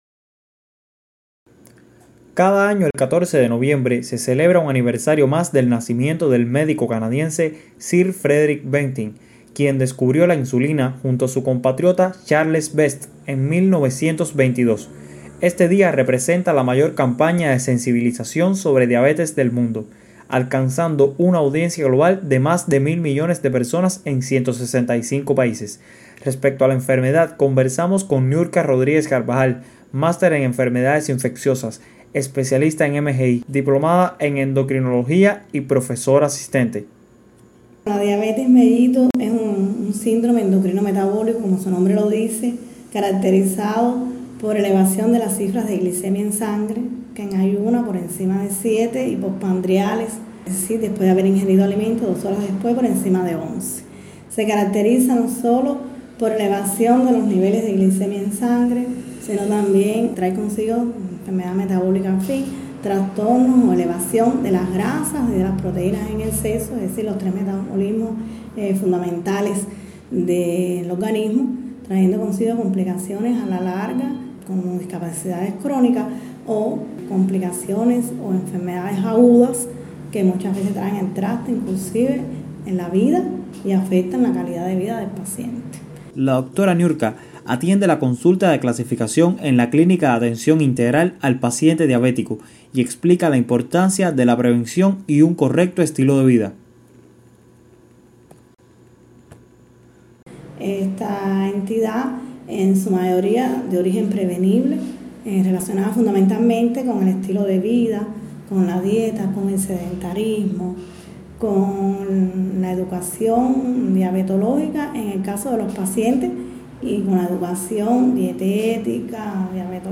entrevista-a-dra-diabetes.mp3